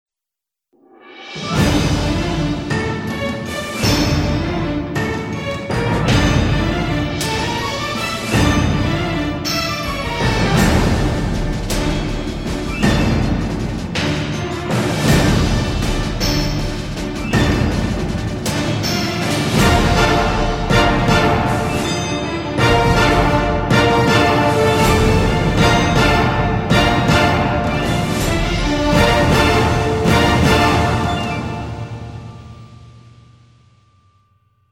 .: orchestral / movie style :.